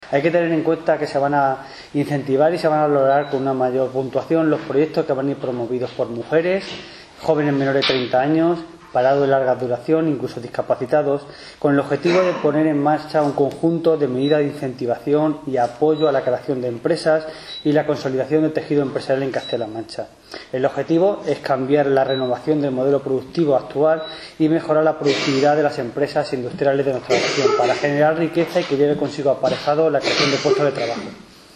“Se van a incentivar y se van a valorar con una mayor puntuación los proyectos promovidos por mujeres, jóvenes menores de 30 años, mayores de 55 años, parados de larga duración y discapacitados, con el objetivo de poner en marcha un conjunto de medidas de incentivación y apoyo a la creación de empresas y la consolidación del tejido empresarial”, ha explicado en rueda de prensa, el Concejal de Desarrollo Empresarial, Jesús del Fresno, que añadió que con ellos “se pretende renovar el modelo productivo actual y mejorar la productividad de las empresas para generar empresas y crear puestos de trabajo”.